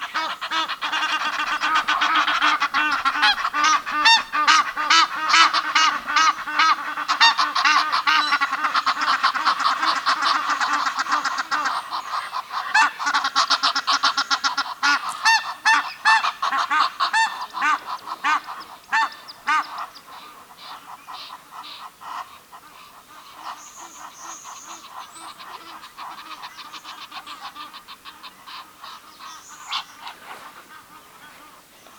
Nijlgans